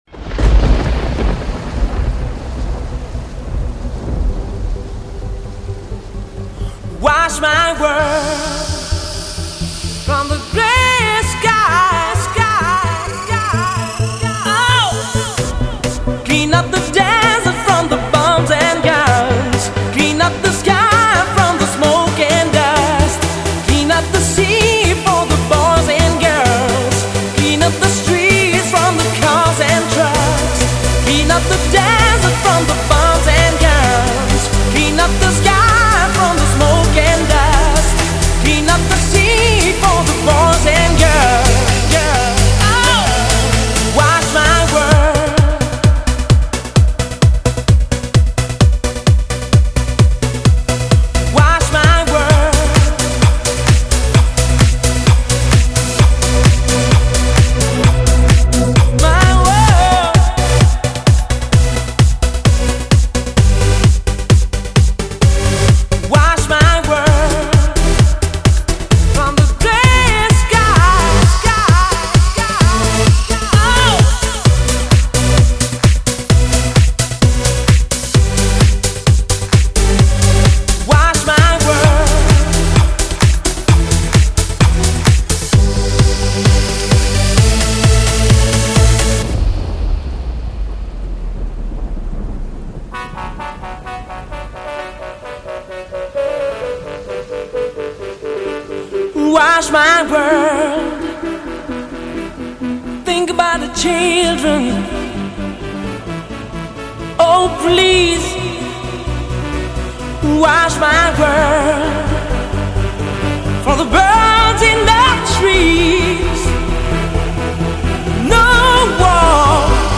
Жанр:Progressive/House